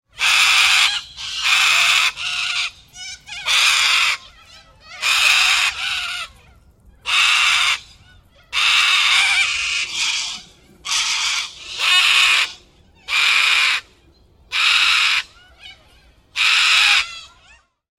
科里亚斯 " 科里亚斯尖叫声多
描述：多个澳大利亚科雷利亚鸟一起尖叫
Tag: 澳大利亚 鸟叫声 尖叫 科雷拉 现场录音 澳大利亚